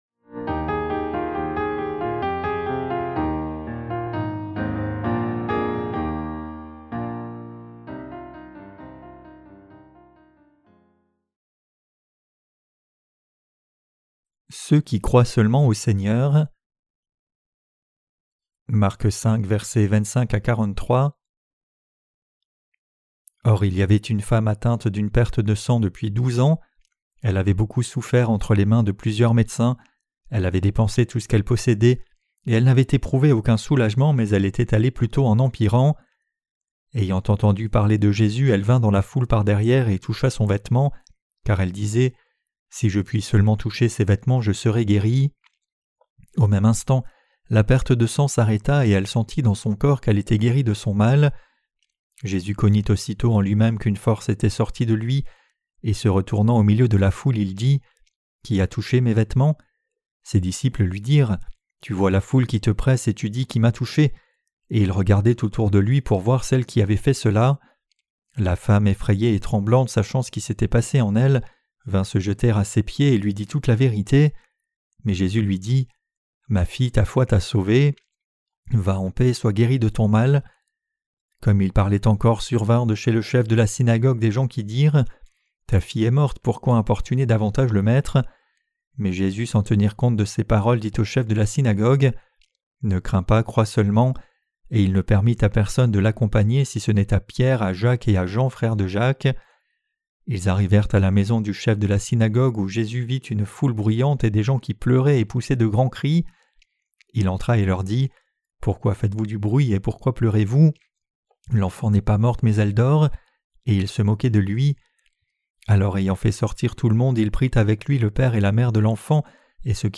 Sermons sur l’Evangile de Marc (Ⅰ) - QUE DEVRIONS-NOUS NOUS EFFORCER DE CROIRE ET PRÊCHER? 13.